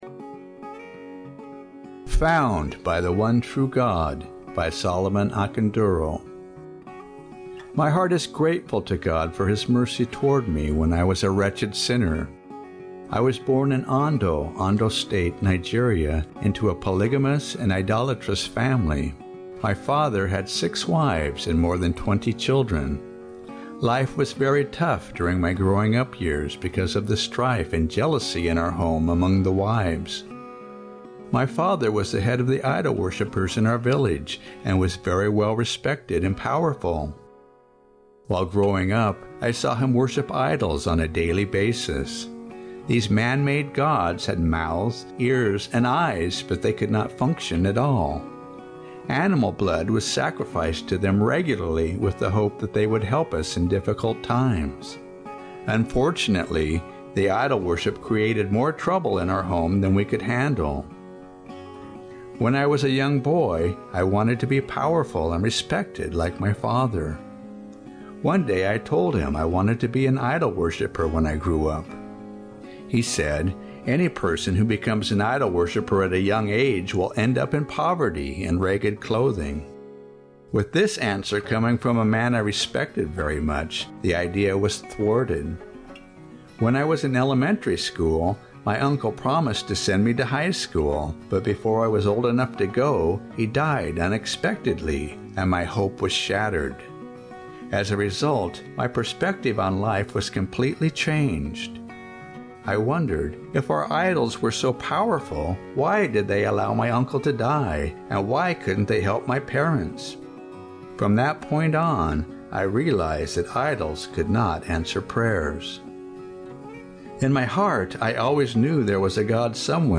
Witness